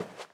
paw_earth2.ogg